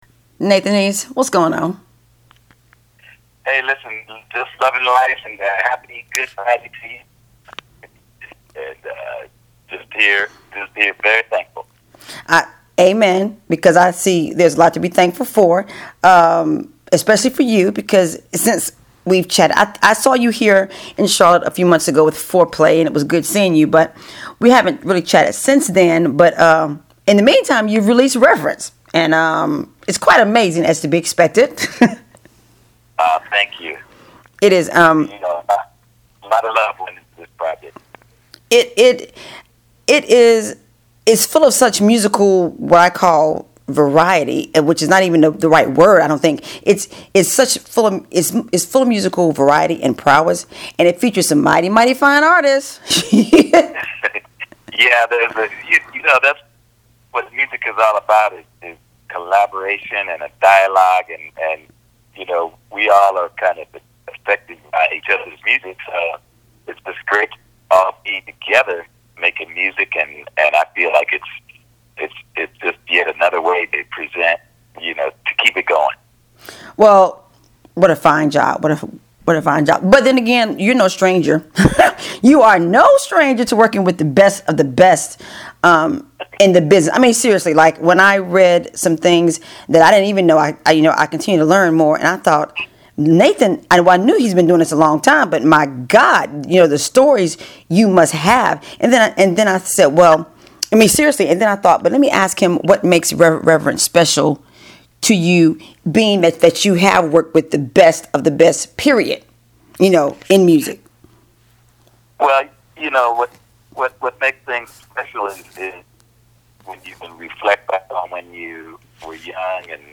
I always enjoy talking with Nathan.